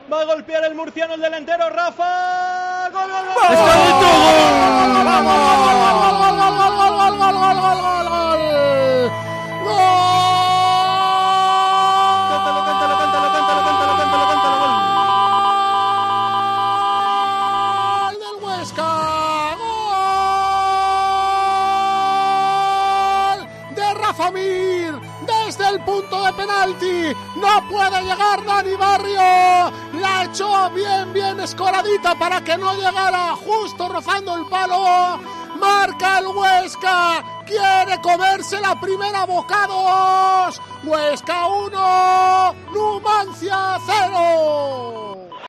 Narración Gol de Rafa Mir / 1-0